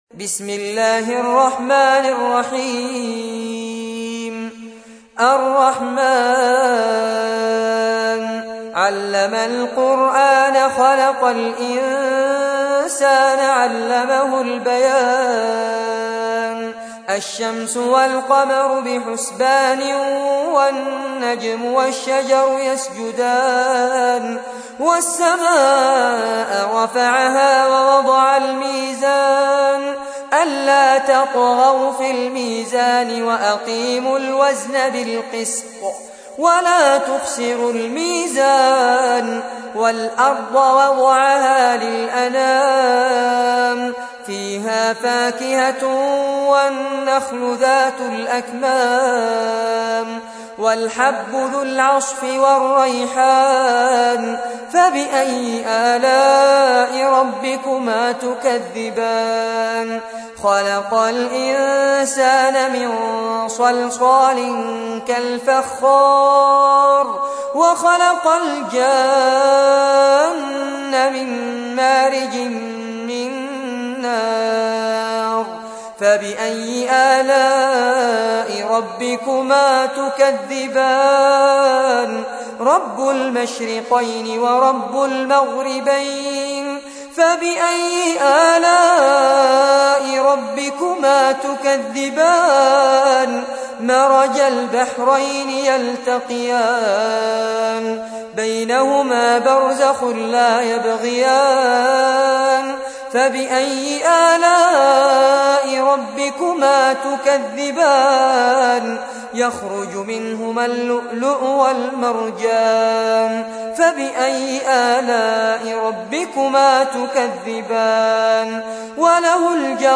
55. سورة الرحمن / القارئ